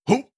22. Effort Grunt (Male).wav